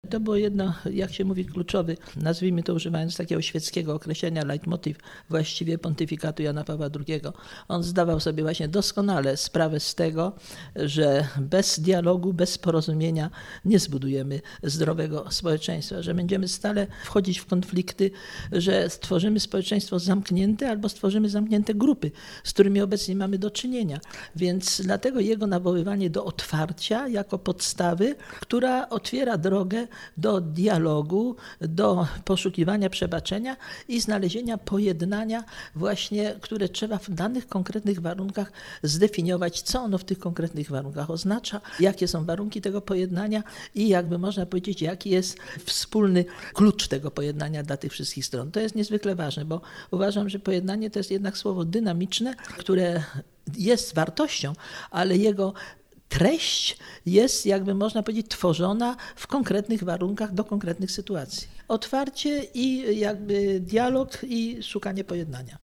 Prof. Hanna Suchocka w trakcie międzynawowej konferencji wygłosiła wykład „Pojednanie jako kategoria kluczowa dla pontyfikatu Jana Pawła II”.